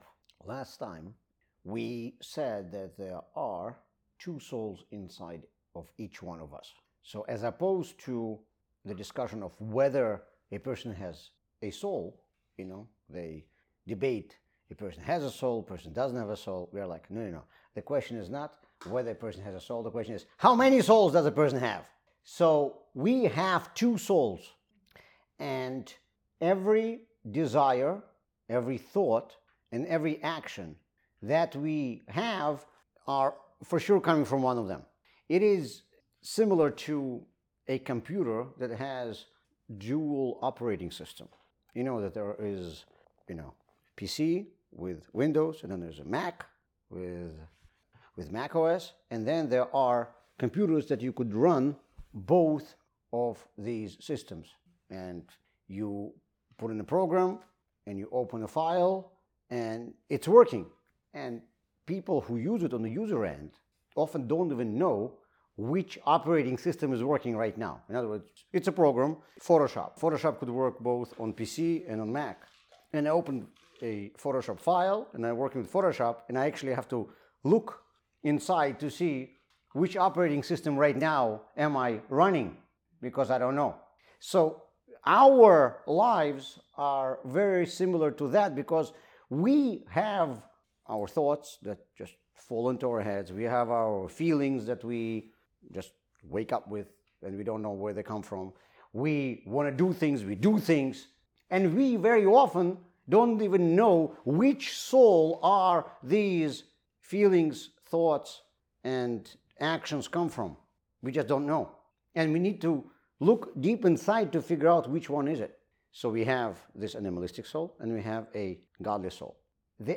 The 2nd class of 3-part series on what Kabalah teaches us about our souls